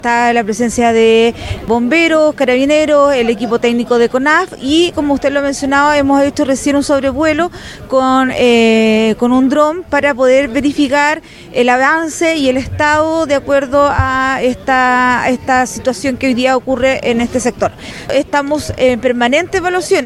La delegada Presidencial de Los Ríos, Carla Peña, dijo que los equipos de emergencia siguen desplegados para evitar que el fuego destruya alguna vivienda.